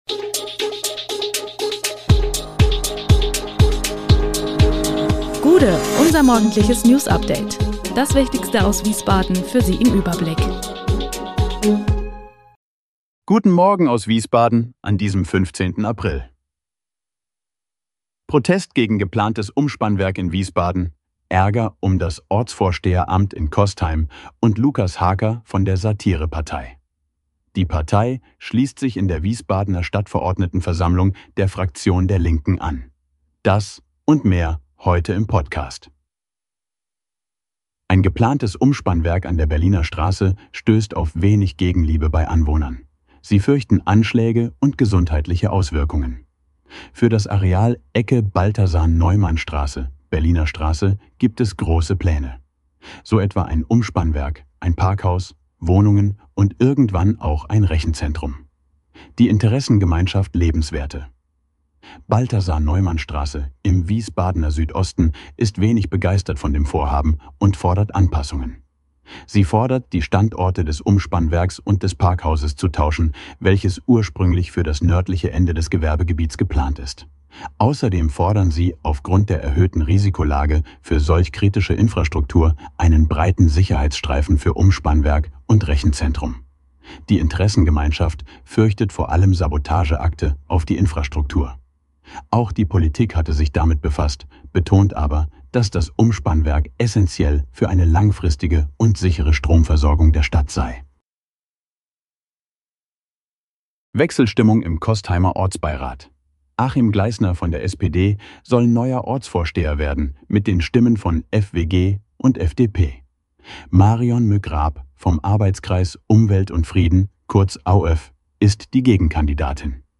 Der Podcast am Morgen für die Region
Nachrichten